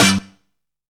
FUNK KEY HIT.wav